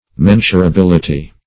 Search Result for " mensurability" : The Collaborative International Dictionary of English v.0.48: Mensurability \Men`su*ra*bil"i*ty\, n. [Cf. F. mensurabilit['e].] The quality of being mensurable.
mensurability.mp3